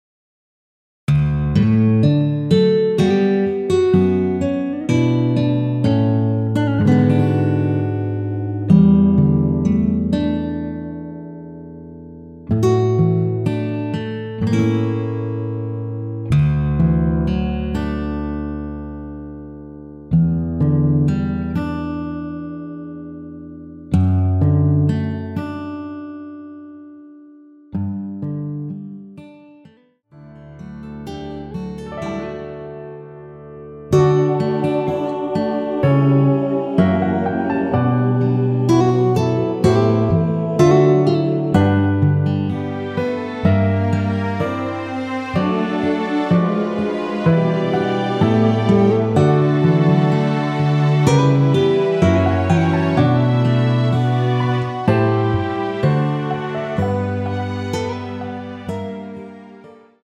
원키에서(-1)내린 MR입니다.
D
앞부분30초, 뒷부분30초씩 편집해서 올려 드리고 있습니다.
중간에 음이 끈어지고 다시 나오는 이유는